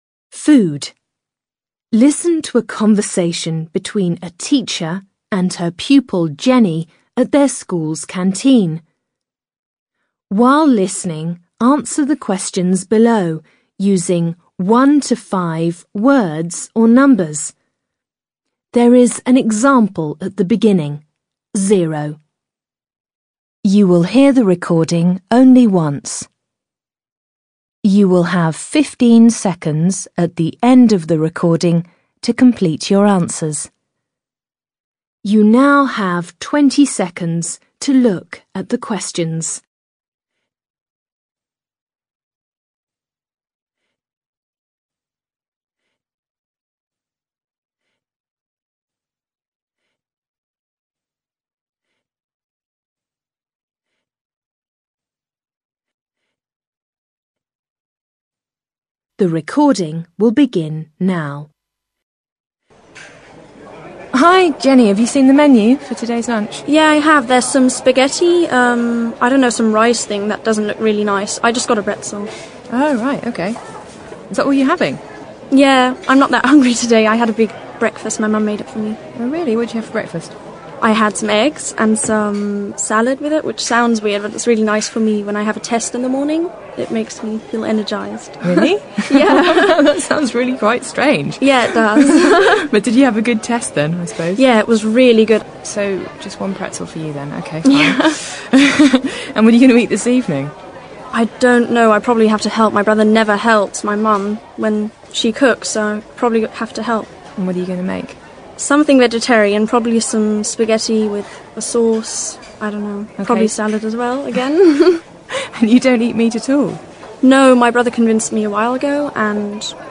Alltagsgespräch